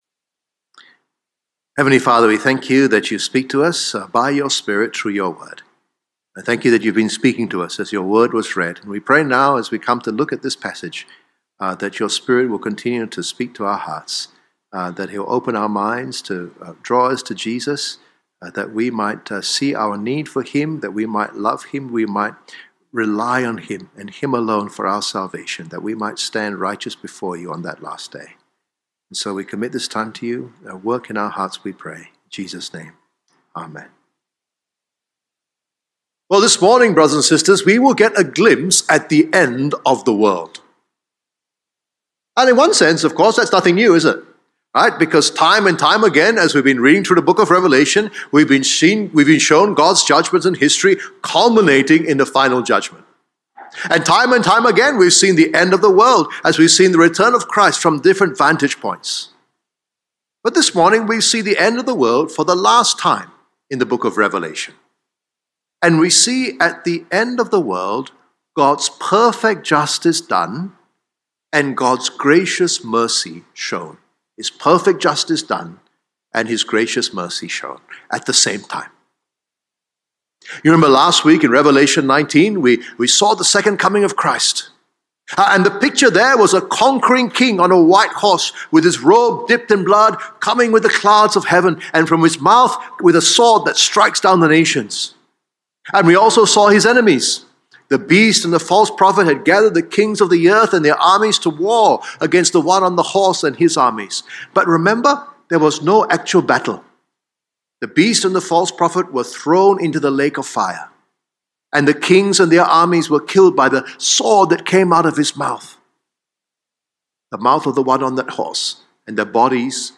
A weekly sermon from the English service at St Mary's Anglican Cathedral, Kuala Lumpur.